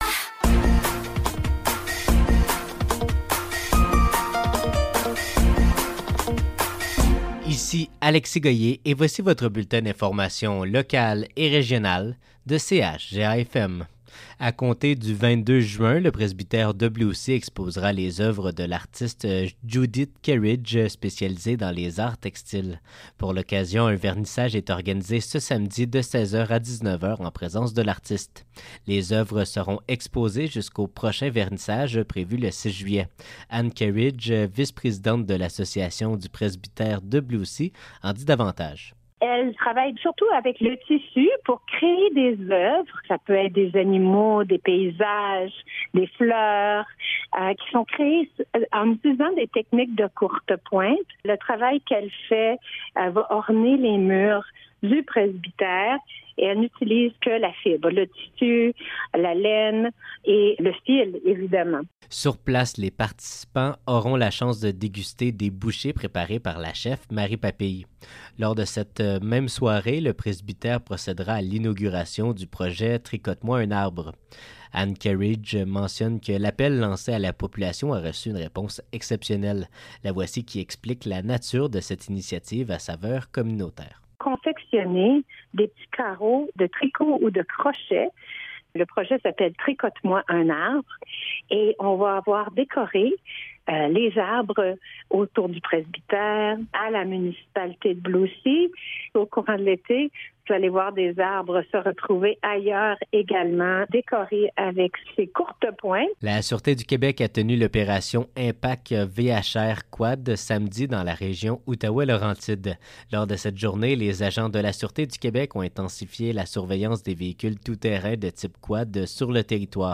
Nouvelles locales - 21 juin 2024 - 12 h